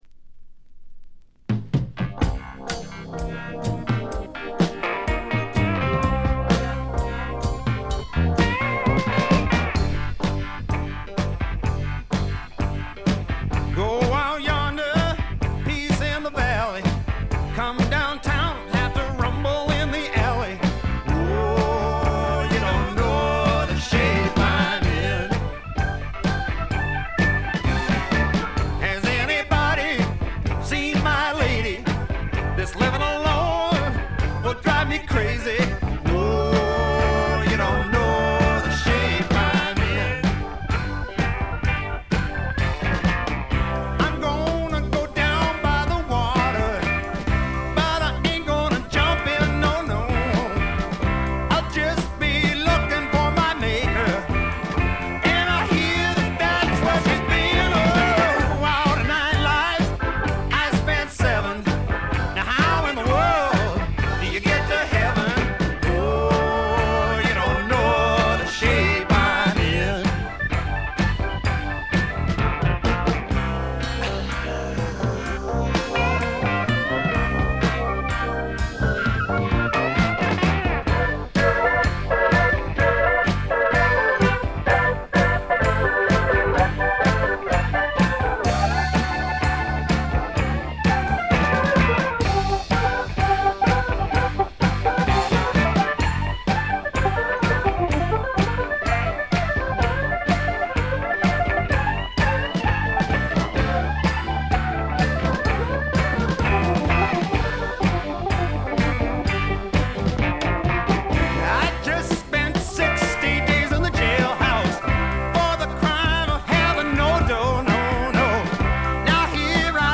ディスク：部分試聴ですがほとんどノイズ感無し。
試聴曲は現品からの取り込み音源です。